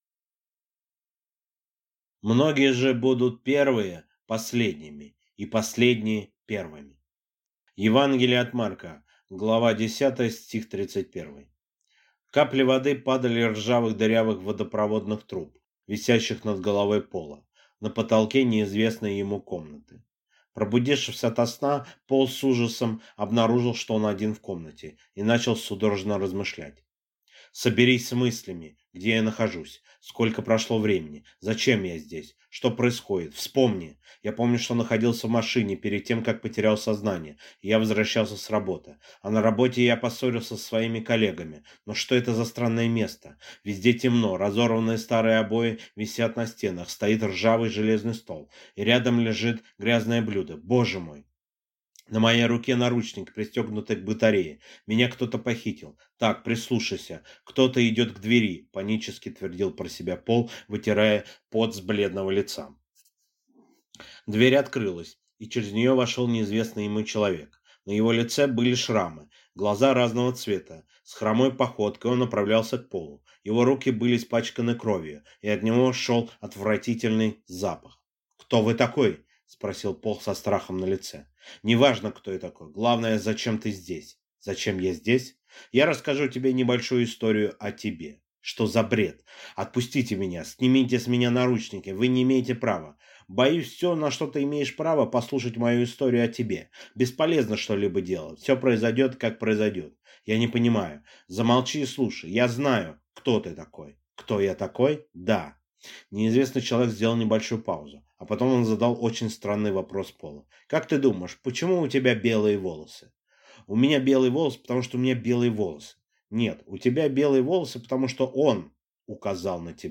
Аудиокнига Он | Библиотека аудиокниг